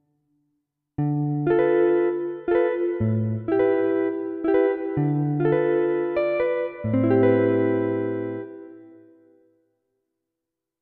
13. I SUONI - GLI STRUMENTI XG - GRUPPO "GUITAR"
11. Jazz Guitar
XG-03-11-JazzGuitar.mp3